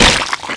zombiedie.mp3